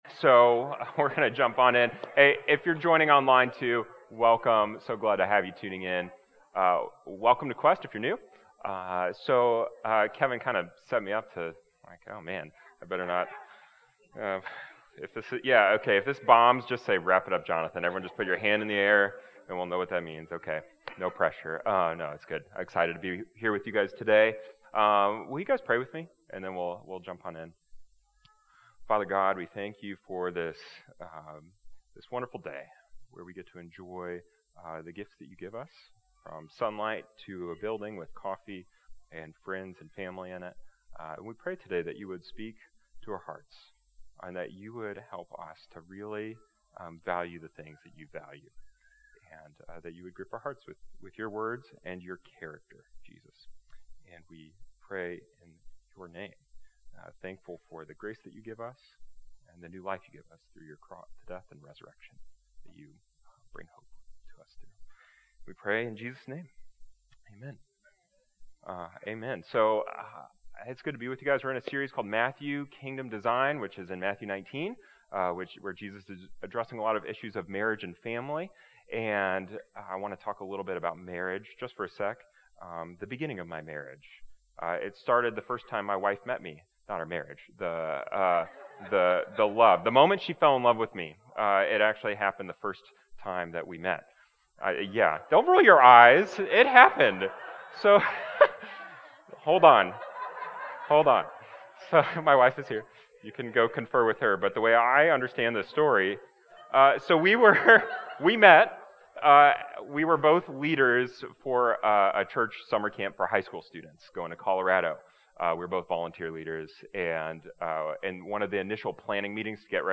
This morning's message comes from Matthew 19 and looks at the topic of the blessing of children.